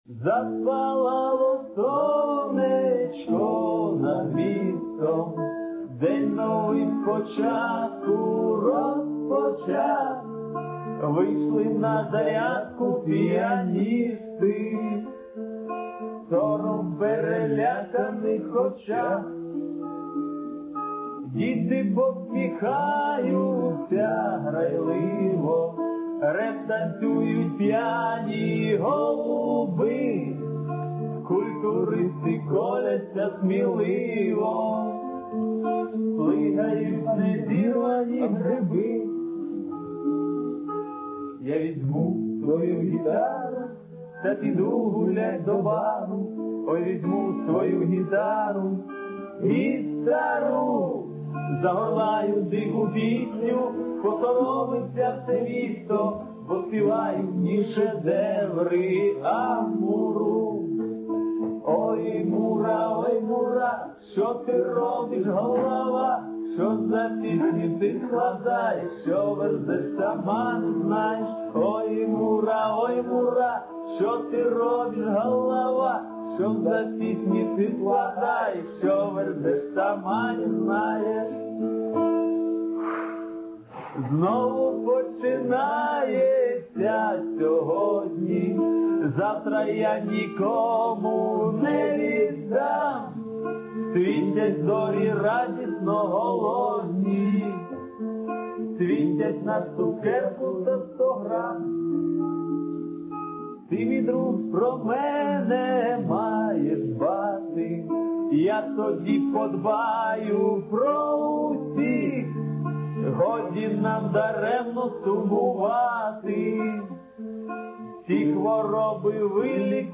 К сожалению у них плохое качество записи, но лучше нету. :-(